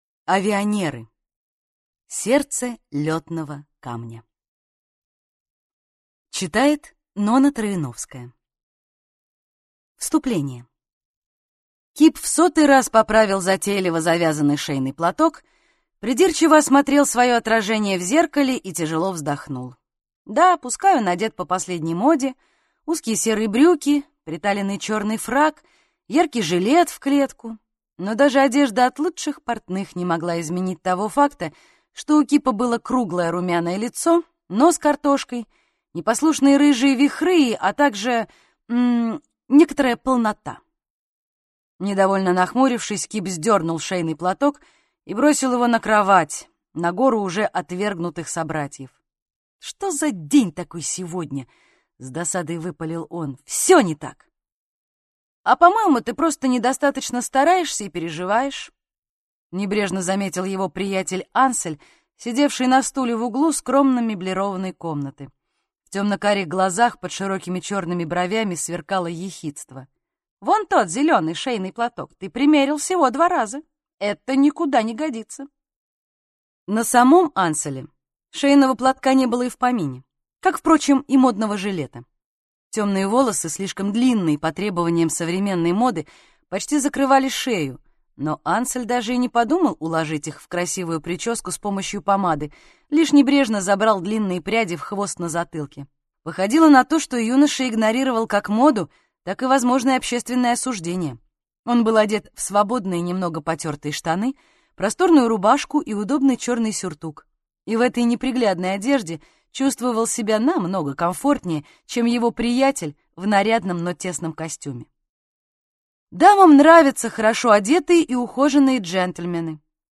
Аудиокнига Сердце лётного камня | Библиотека аудиокниг